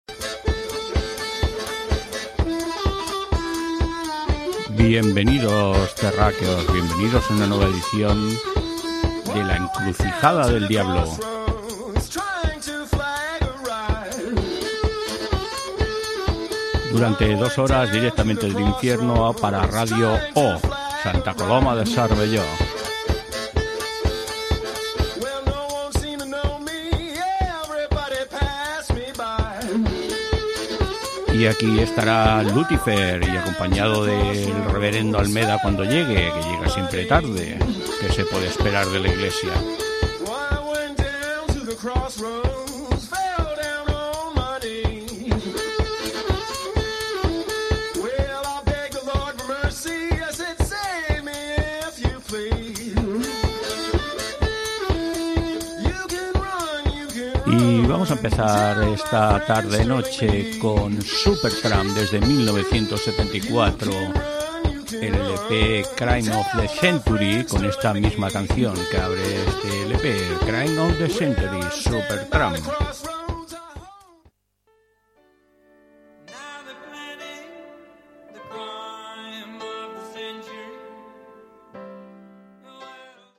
Presentació amb la identificació de la ràdio i tema musical
Musical